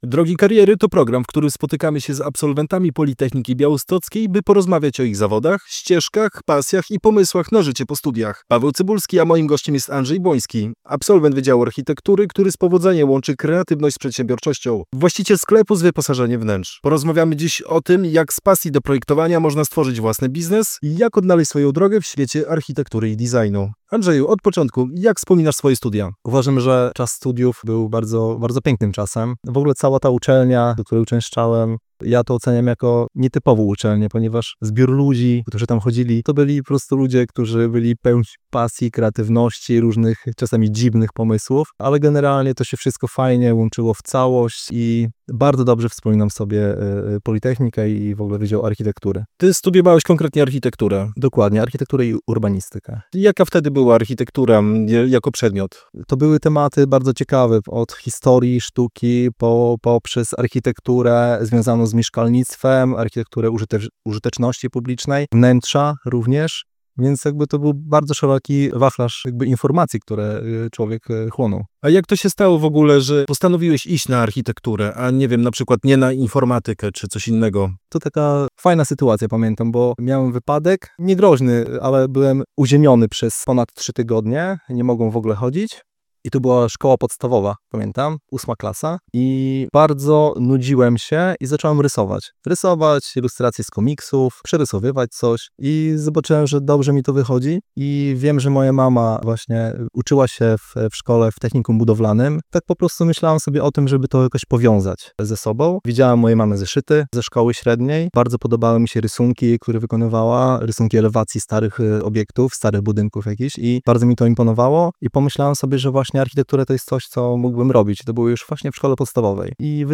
Drogi kariery to program, w którym spotykamy się z absolwentami Politechniki Białostockiej, by porozmawiać o ich ścieżkach zawodowych, pasjach i pomysłach na życie po studiach.